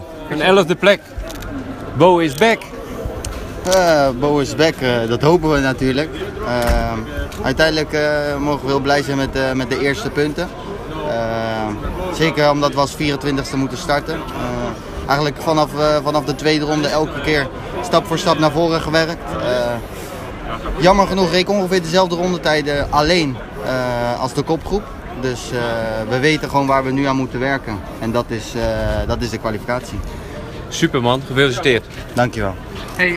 Eerste (audio) reactie Bo Bendsneyder direct na afloop van de race: